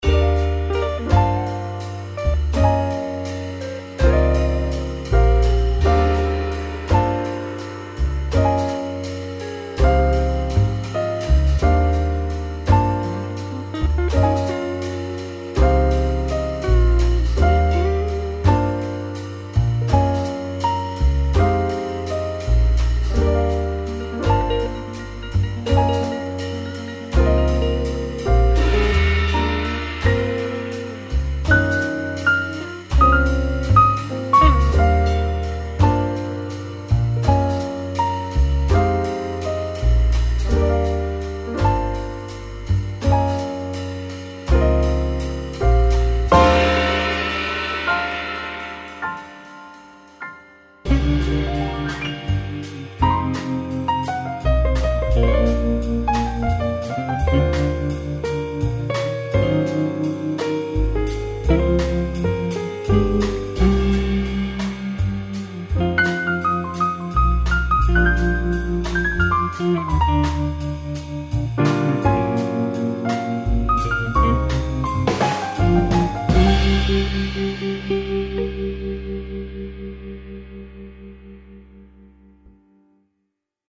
14 Doublebass Loops
10 Guitar Loops
28 Jazz Ensemble Loops
13 Piano Loops